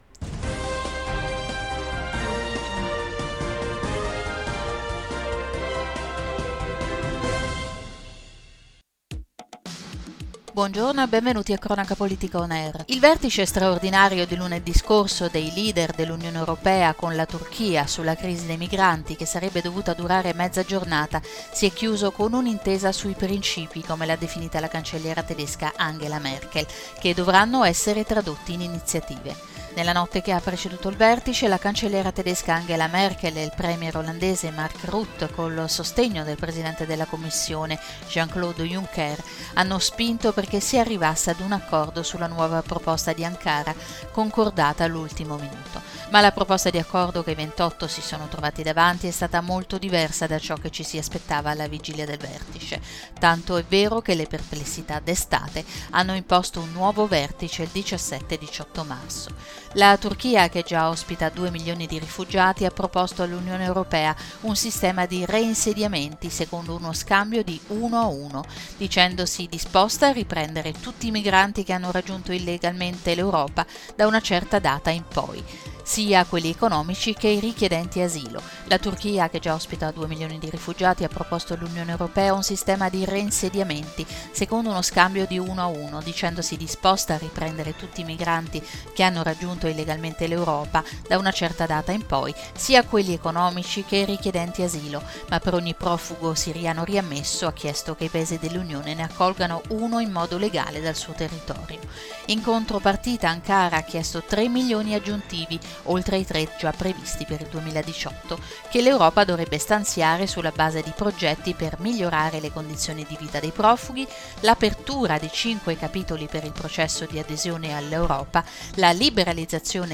Notiziario 10/03/2016 - Cronaca politica